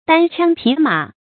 注音：ㄉㄢ ㄑㄧㄤ ㄆㄧˇ ㄇㄚˇ
單槍匹馬的讀法